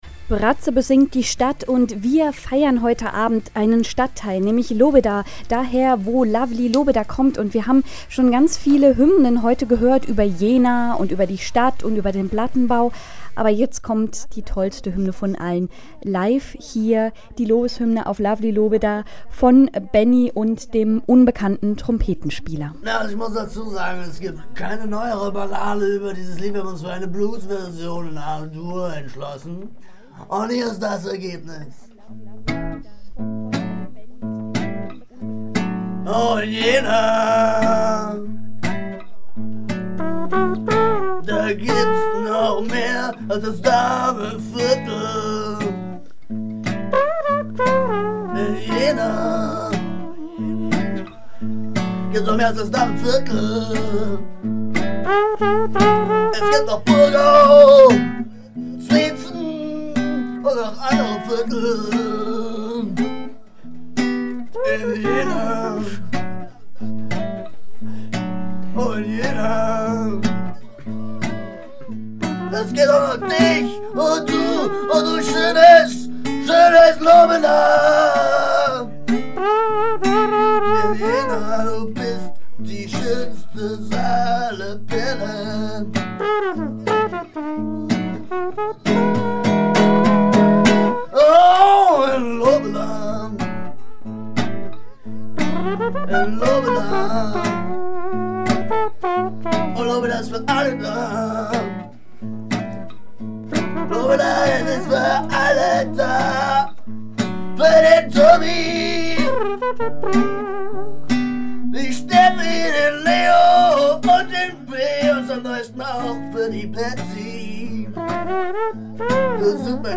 Einmalig bitter und verraucht – kantig wie der Beton in Lobedas abgehangendster Platte.
an der Gitarre und ein unbekannter Trompetenspieler